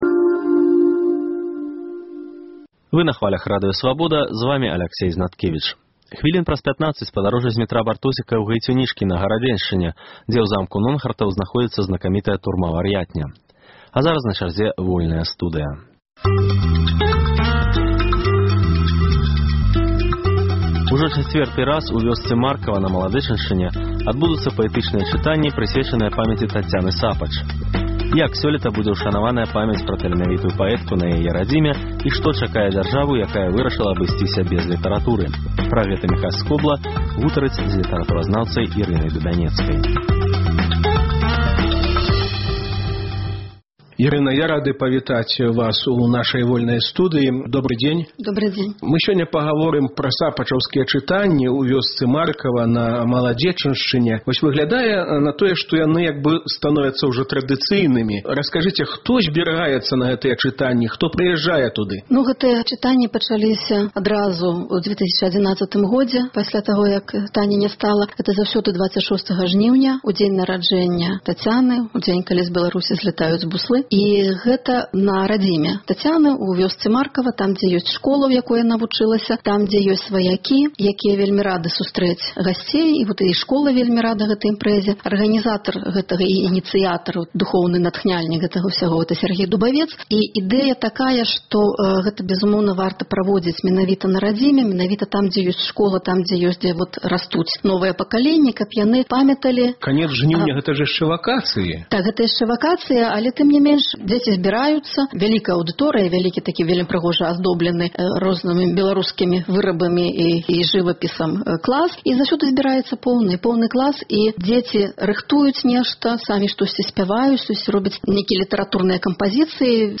Госьцем перадачы – культуроляг